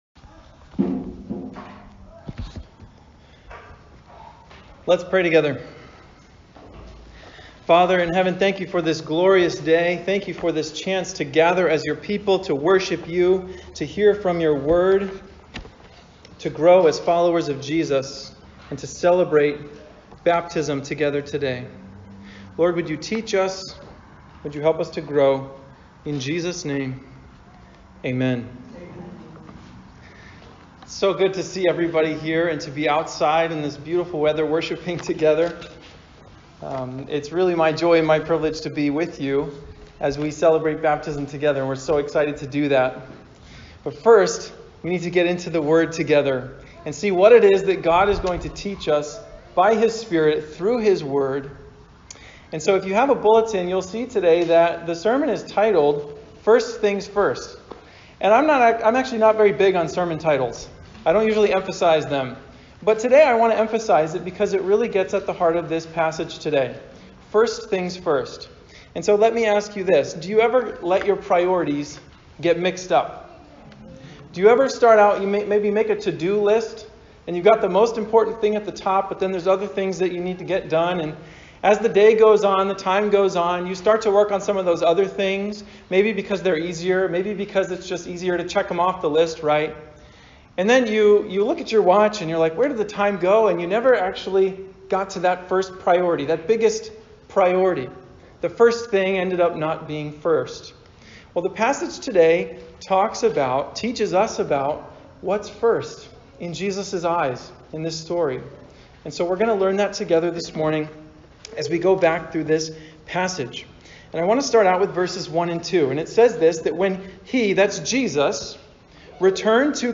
Grace Baptism Service